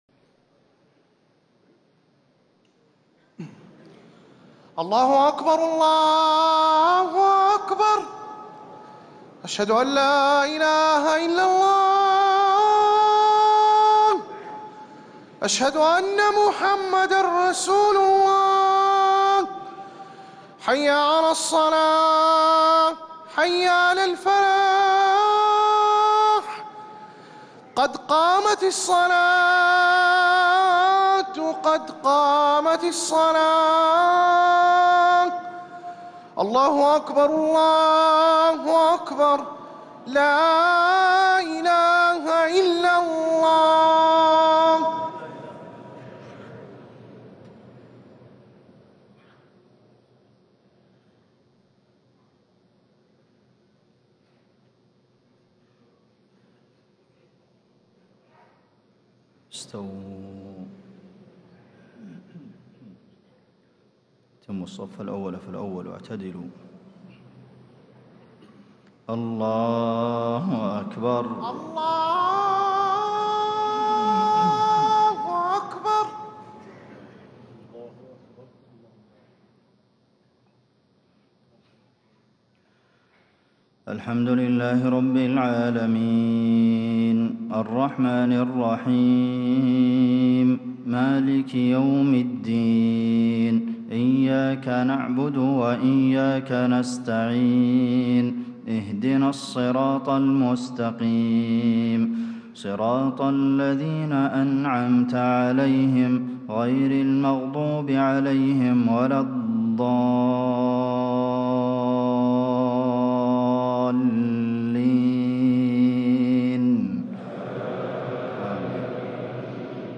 صلاة العشاء 1 ربيع الأول 1437هـ من سورة البقرة 150-158 > 1437 🕌 > الفروض - تلاوات الحرمين